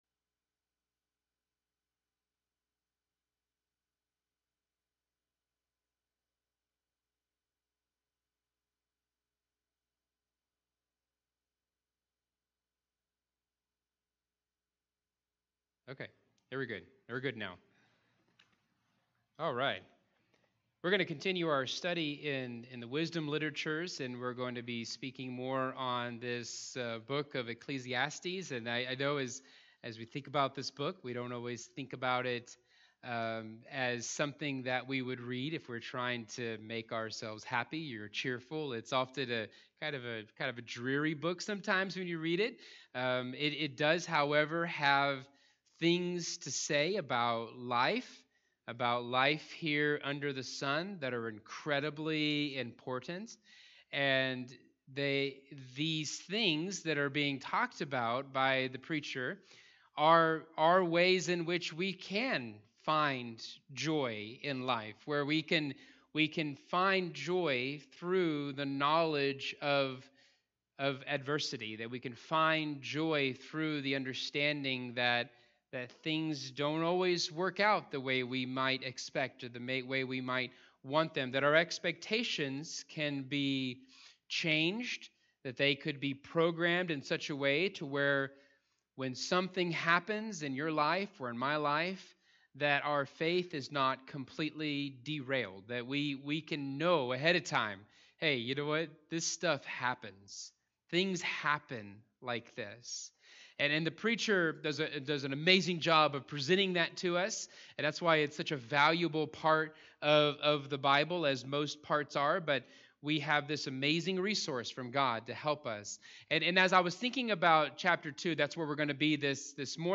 All Sermons Under The Sun